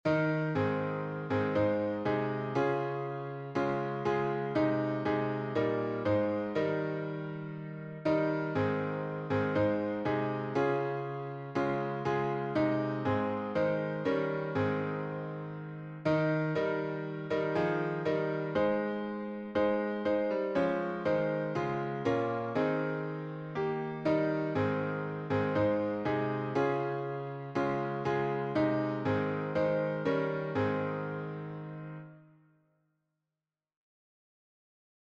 #4104: Stand Up, Stand Up for Jesus — A flat major — Webb | Mobile Hymns
Key signature: A flat major (4 flats) Time signature: 4/4